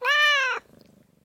animalia_cat_idle.ogg